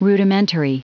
Prononciation du mot : rudimentary
rudimentary.wav